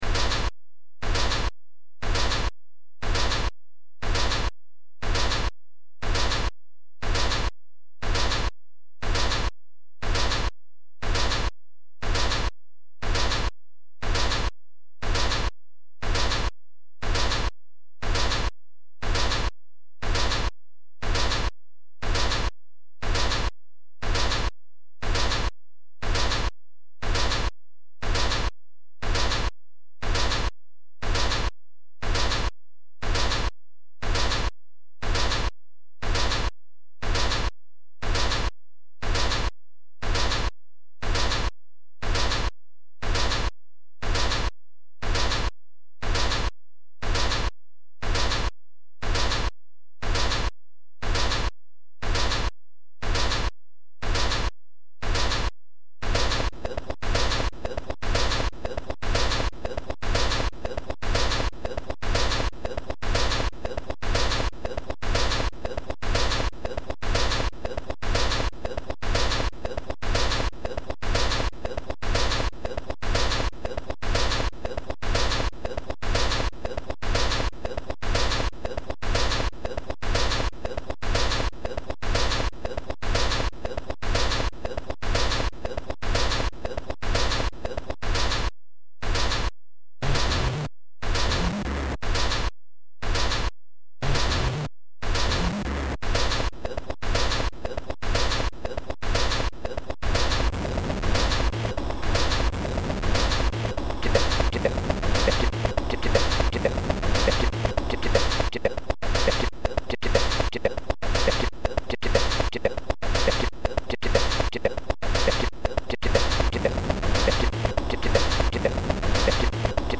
is a collection of lo-fi electronic dance tracks.
This is raw clicks'n'cuts for the new millennium.